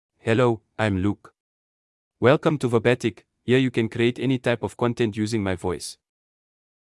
Luke — Male English (South Africa) AI Voice | TTS, Voice Cloning & Video | Verbatik AI
MaleEnglish (South Africa)
Luke is a male AI voice for English (South Africa).
Voice sample
Male